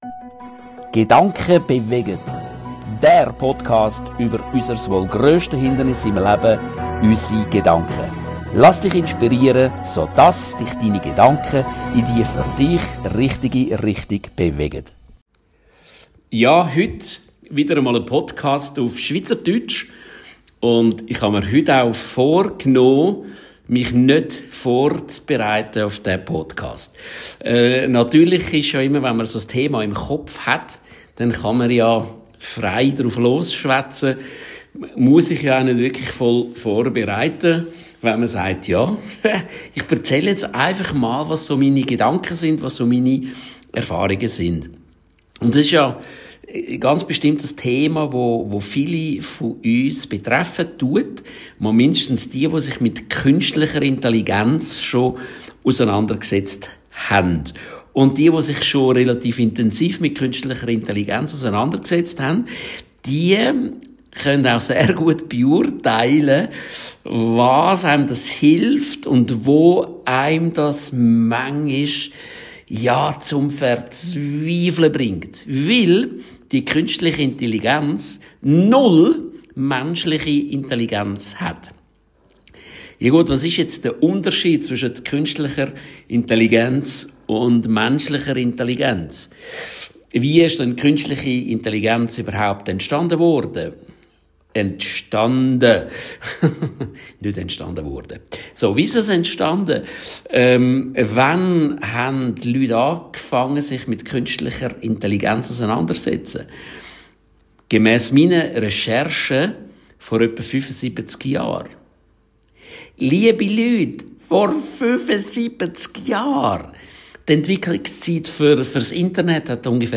kein skript. keine notizen.